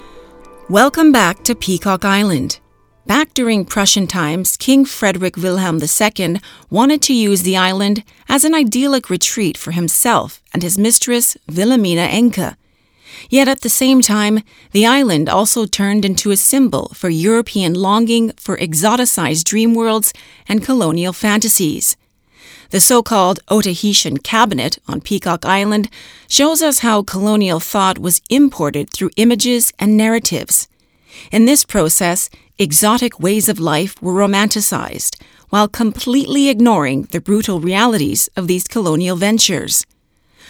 Locutor
Hablante nativo